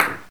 soft-hitclap.ogg